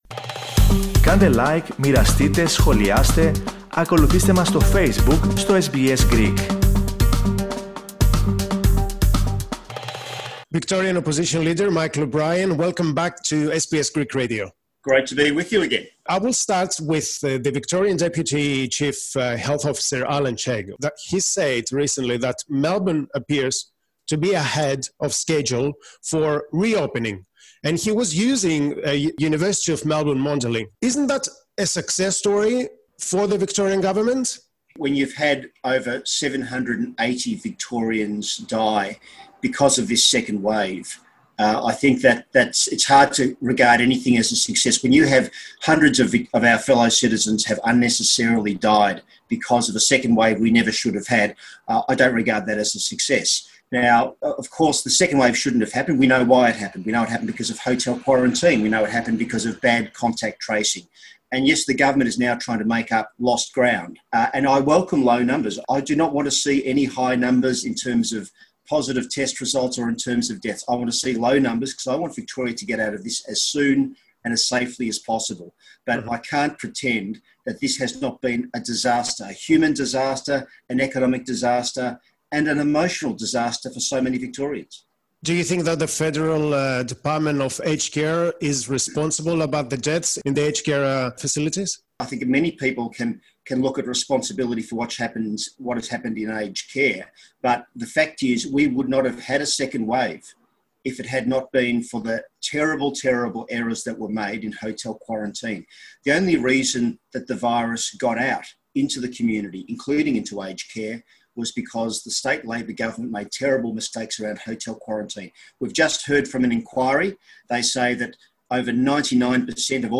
Victorian Opposition Leader Michael O'Brien talks to SBS Greek about Victoria's deadly second COVID-19 wave, the Daniel Andrews Government responsibilities, the role of former Health Minister Jenny Mikakos, the deaths of Greek Victorians, the rhetoric of the party's MPs, and the letters he gets from his Greek constituents regarding the taxes.
michael_obrien_on_sbs_greek_radio.mp3